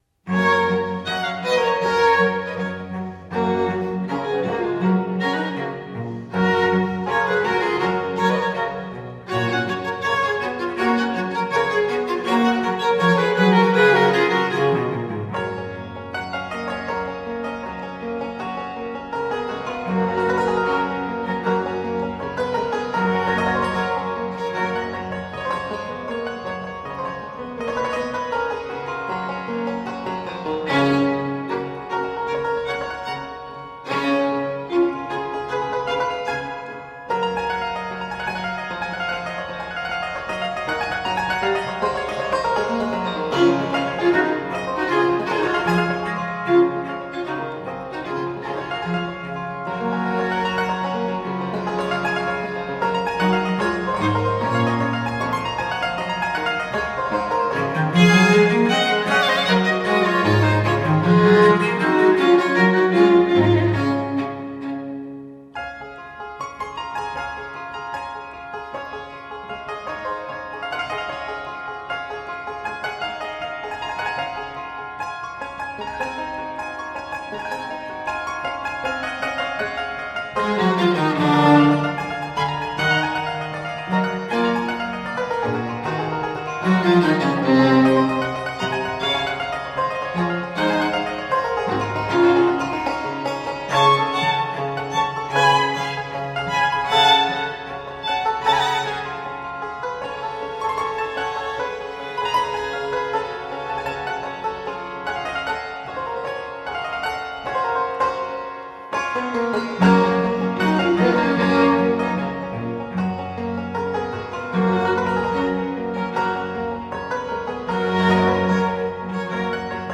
Concerto in G
Allegretto